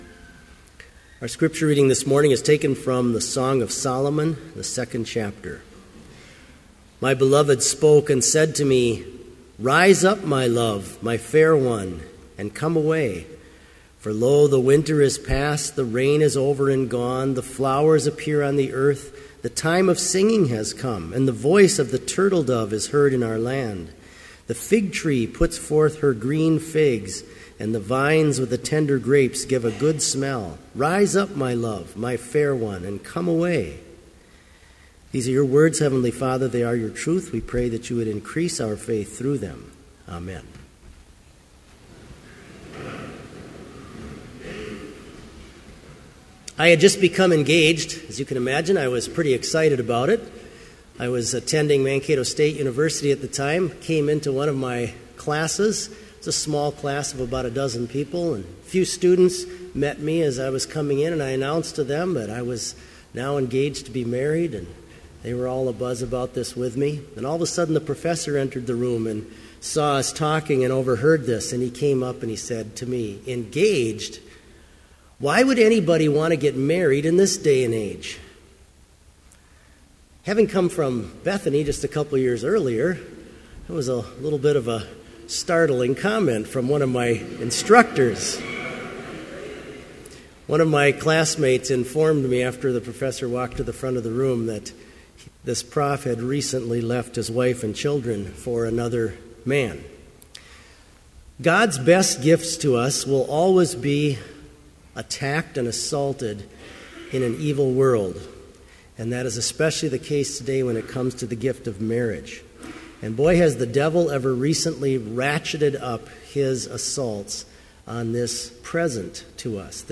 Complete service audio for Chapel - January 20, 2014